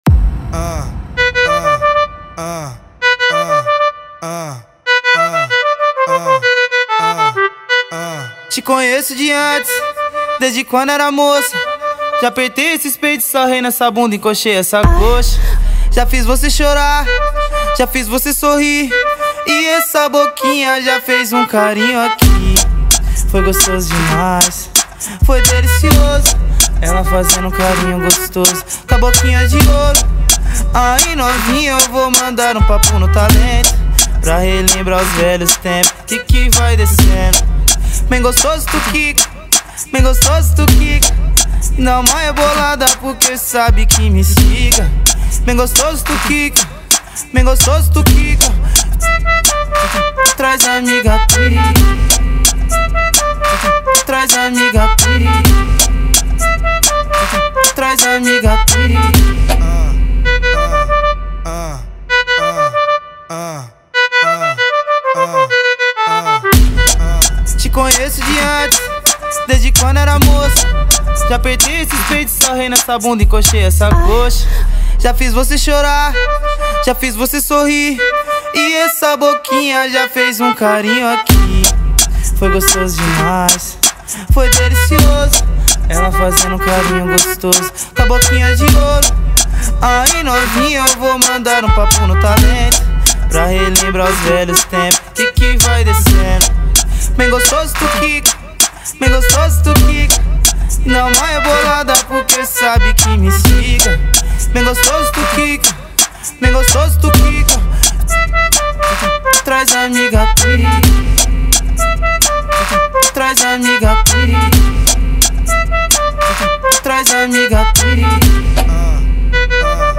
2025-01-30 23:52:19 Gênero: Funk Views